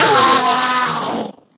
sounds / mob / endermen / death.ogg
death.ogg